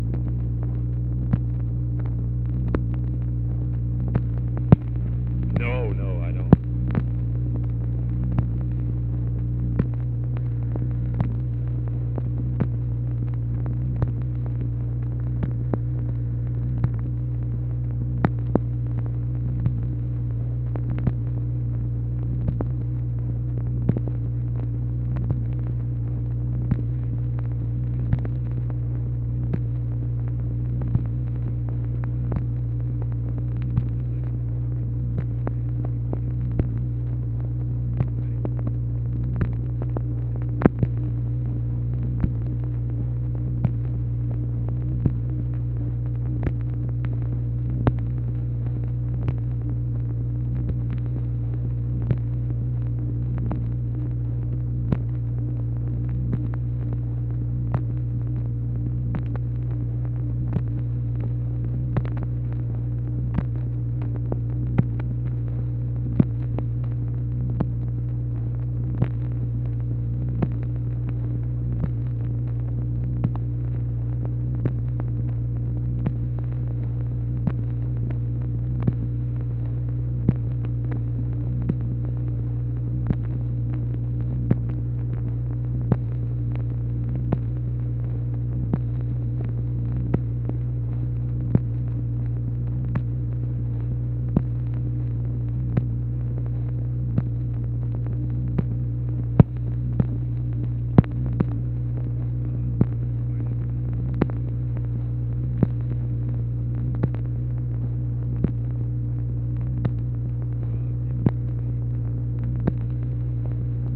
ONLY AUDIBLE WORDS ARE "NO, NO, I DON'T"
Conversation with UNIDENTIFIED MALE, January 1, 1964